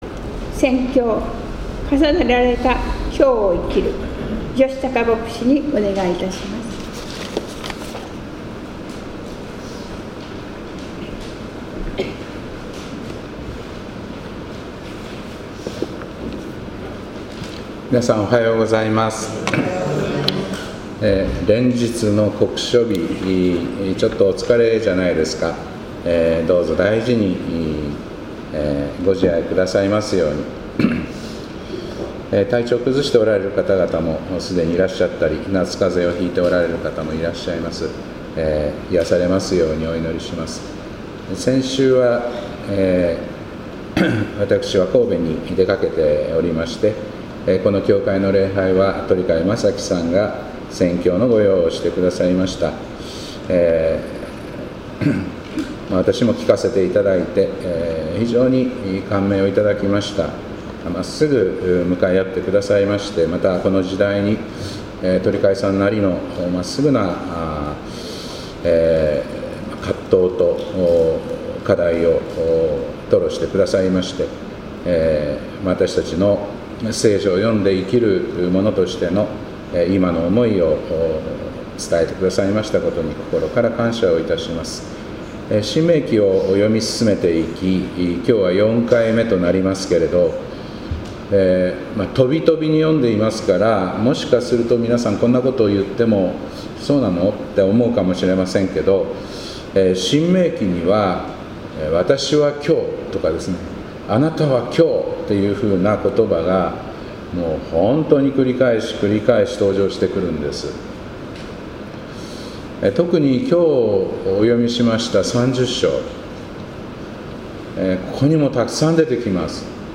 2025年8月24日礼拝「重ねられた今日を生きる」